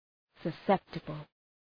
Προφορά
{sə’septəbəl}